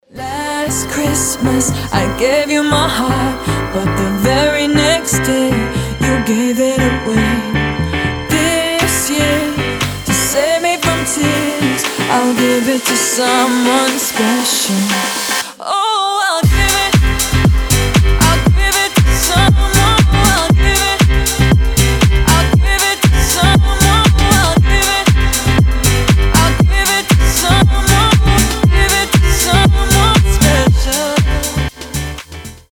• Качество: 320, Stereo
Cover
house
рождественские
ремиксы